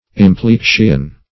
Search Result for " implexion" : The Collaborative International Dictionary of English v.0.48: Implexion \Im*plex"ion\, n. [L. implexio.]